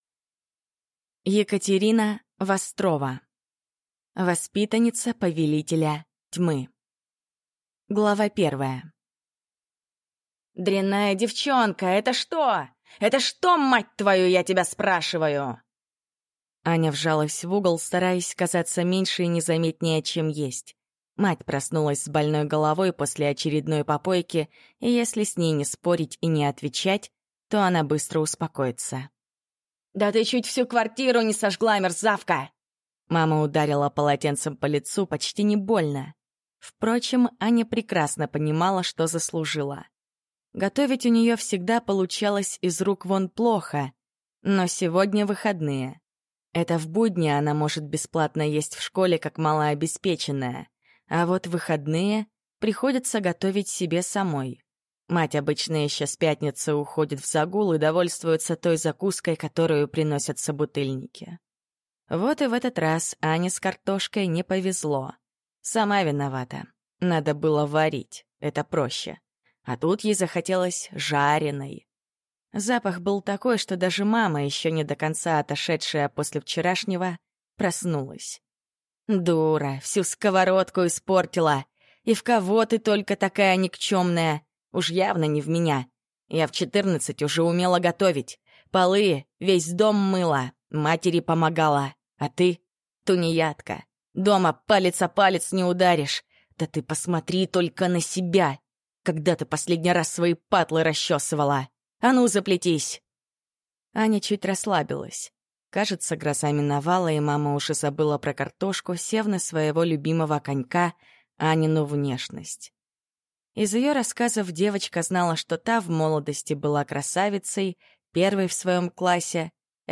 Аудиокнига Воспитанница повелителя тьмы | Библиотека аудиокниг